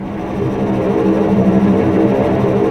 Index of /90_sSampleCDs/Roland LCDP08 Symphony Orchestra/STR_Vcs Bow FX/STR_Vcs Trem p